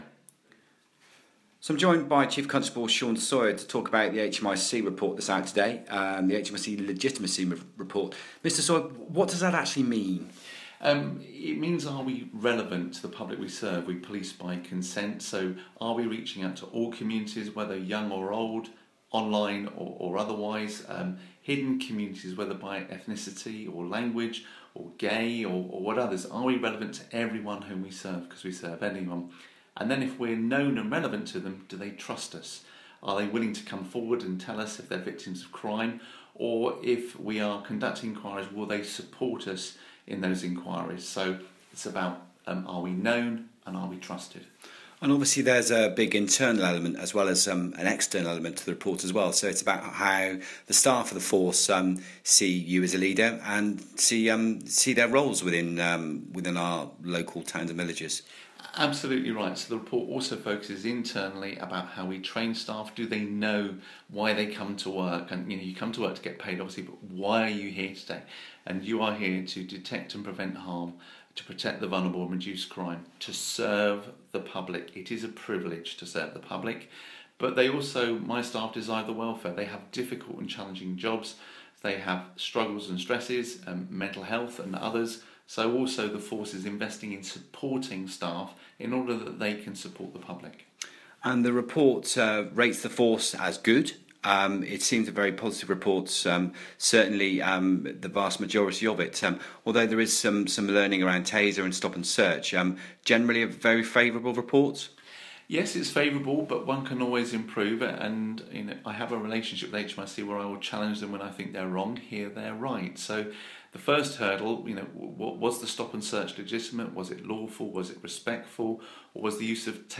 Chief Constable Shaun Sawyer talking about the HMIC Legitimacy report which rates the Force as 'Good' and says Devon and Cornwall is a 'Fair' Force.